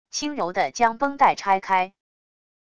轻柔的将绷带拆开wav音频